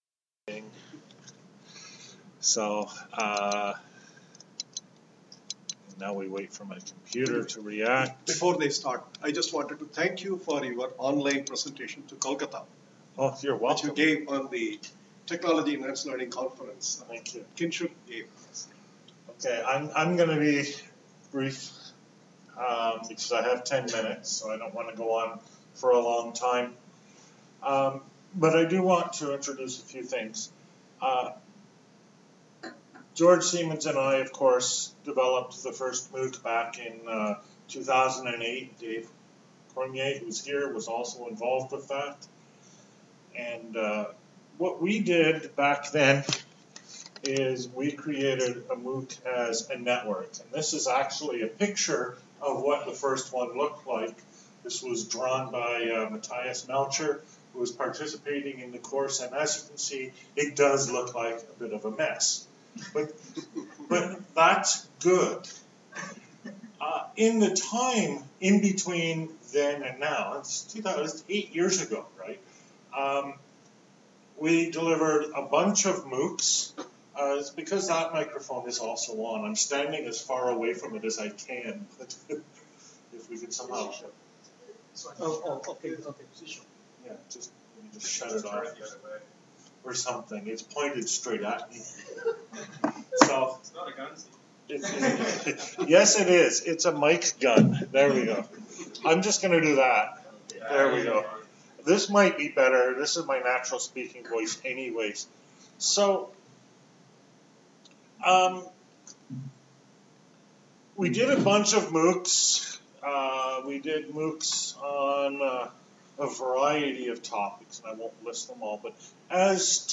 This is a short presentation at an expert's meeting on my thoughts about MOOC quality. I focus on the elements of personal learning and present the four elements of MOOC quality, autonomy, openness, interactivity.